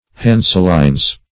Hanselines \Han"sel*ines\ (h[aum]n"sel*[imac]nz), n.